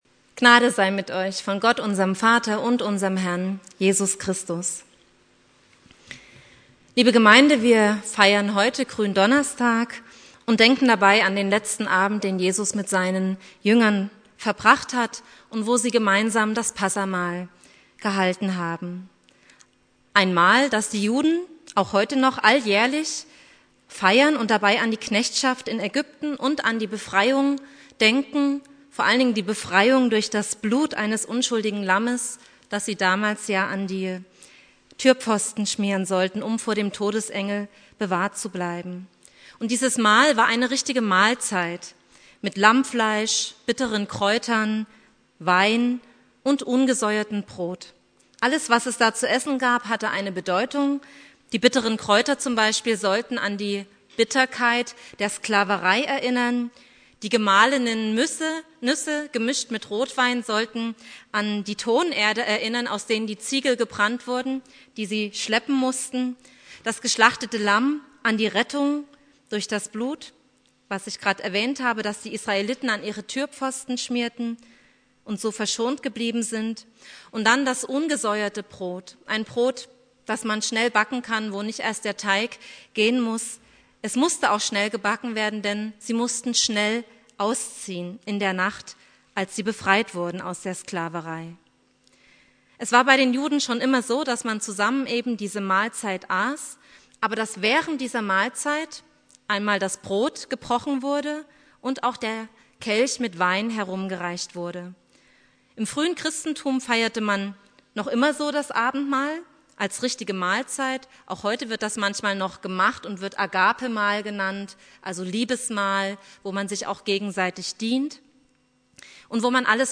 Predigt
Gründonnerstag Prediger